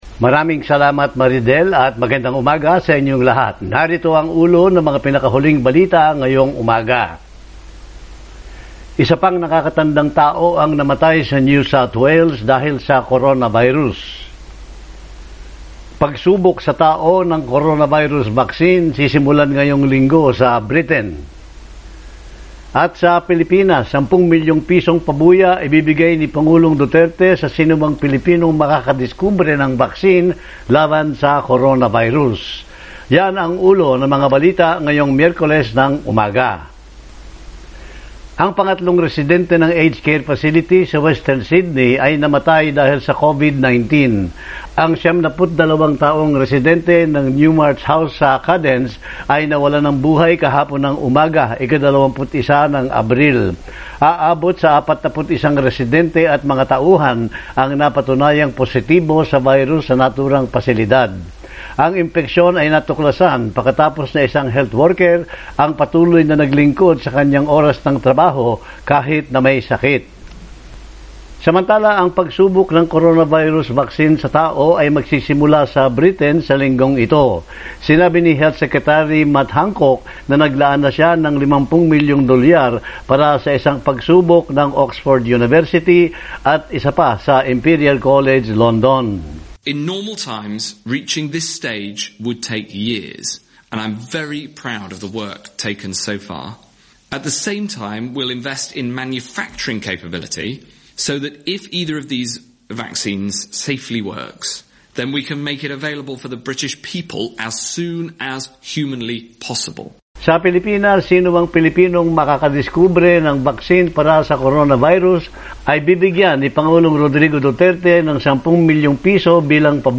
morning_news_22_apr.mp3